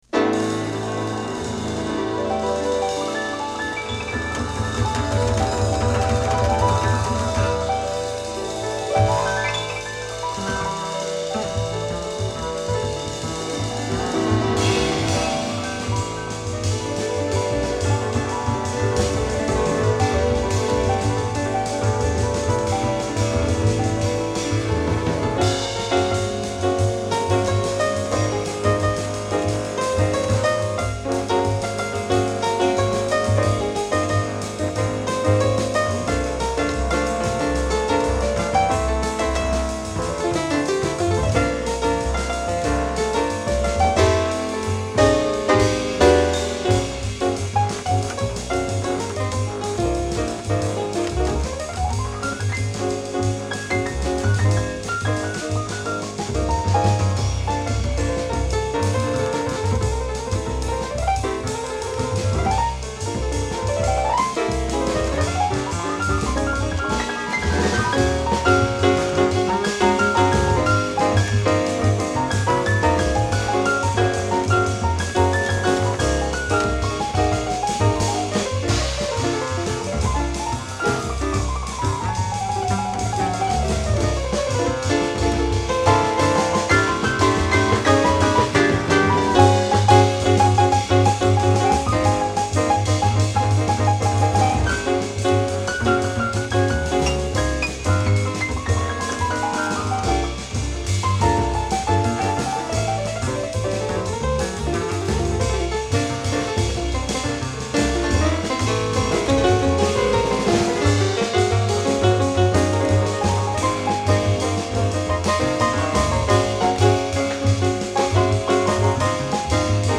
まるでピアノが自在に歌っているかのような美しい旋律に心奪われる、北欧ピアノトリオの隠れた名作。